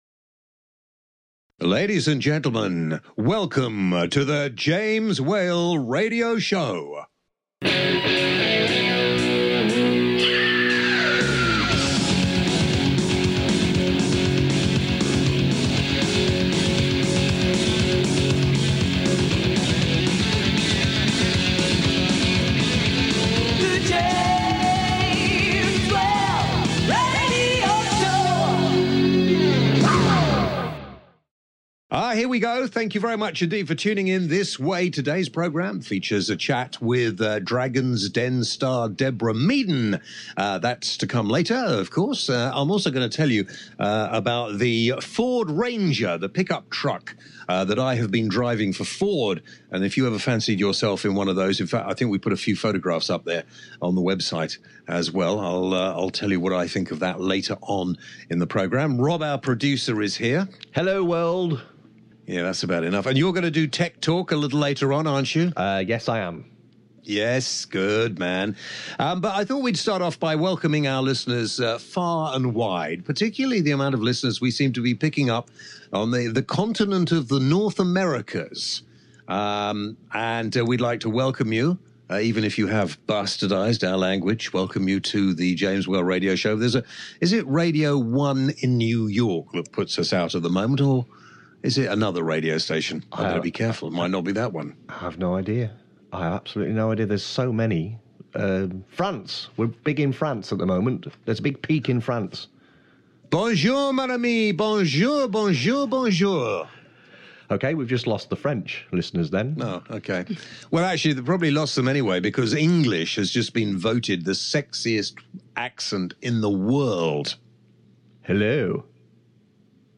This week James talks to Deborah Meaden from Dragons Den about climate change, and whats it’s like being a Dragon.